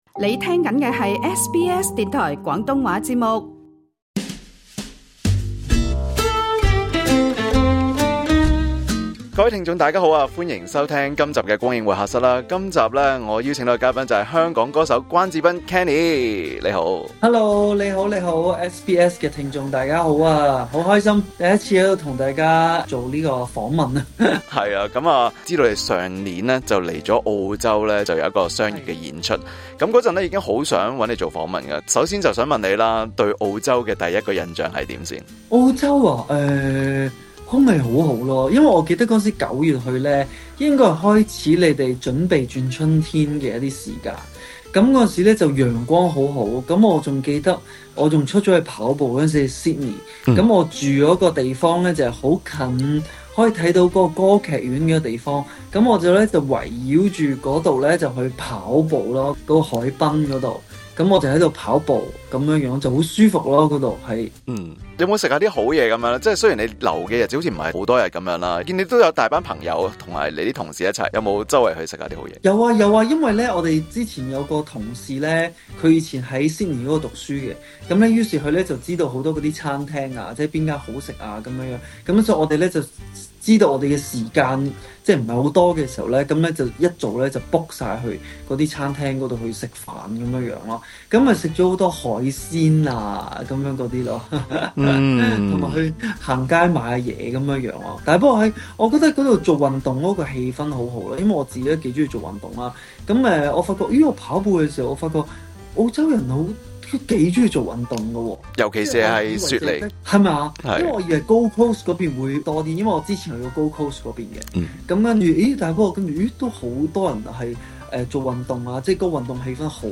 【關智斌專訪】運動帶來自律 舞台上的彼得潘鍛煉出更好的自己